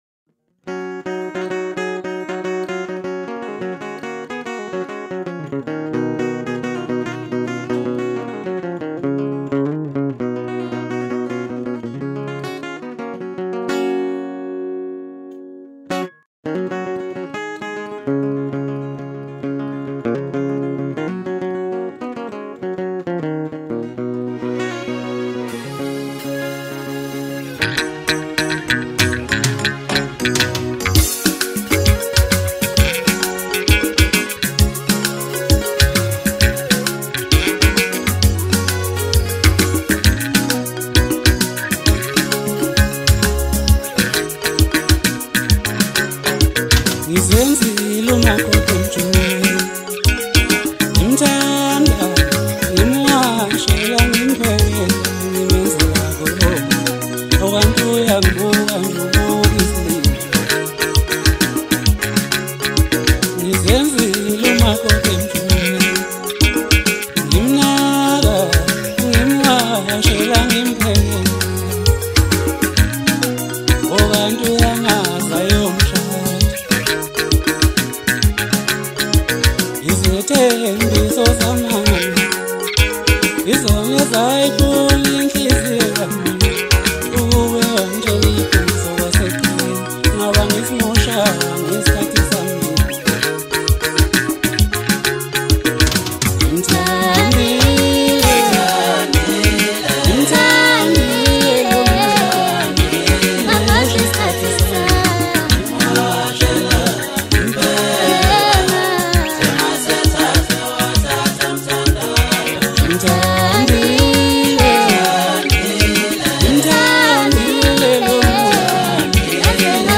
Home » Hip Hop » Latest Mix » Maskandi
is a groovy anthem
blends rhythm and melody flawlessly